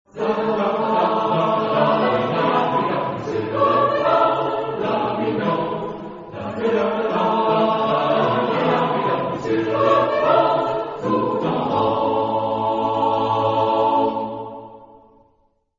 Caractère de la pièce : swing
Type de choeur : SATB  (4 voix mixtes )
Solistes : Alt (1)  (1 soliste(s))
Instruments : Accordéon (ad lib)
Tonalité : ré majeur